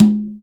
VEC3 Percussion 062.wav